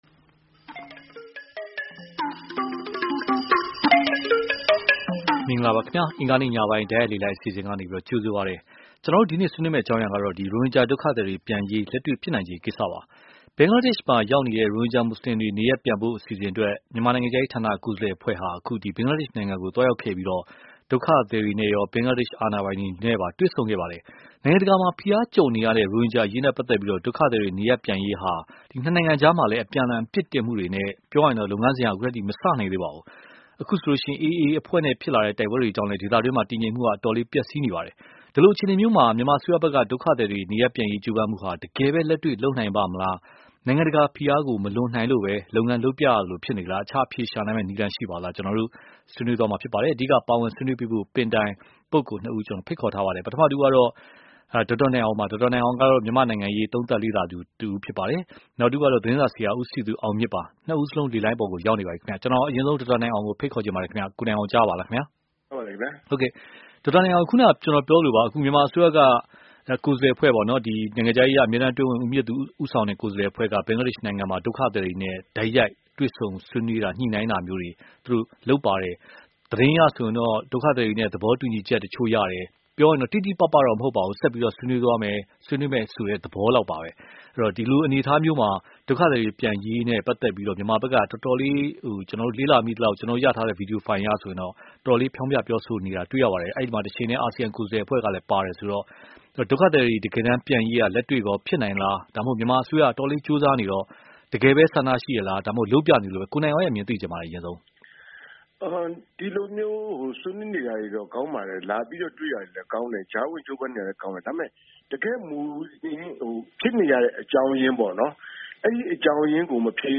ဒုက္ခသည်များနေရပ်ပြန်ရေး (တိုက်ရိုက်လေလှိုင်း)